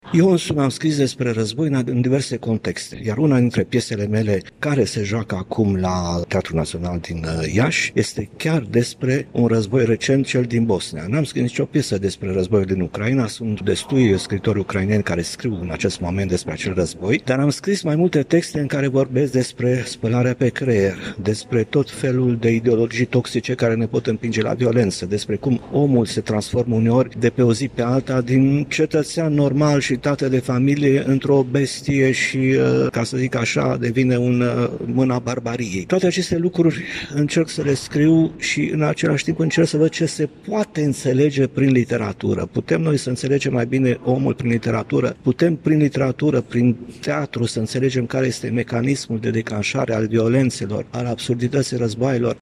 Afirmația a fost făcută astăzi de către scriitorul Matei Vișniec, la Universitatea ”Alexandru Ioan Cuza” din Iași, unde a conferențiat pe tema ”Literatură Dramatică și Război”.